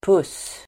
Uttal: [pus:]